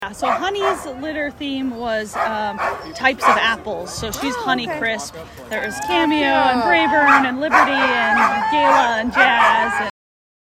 Current Location: Downtown Anchorage at the Ceremonial Iditarod Start